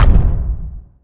footstep2.wav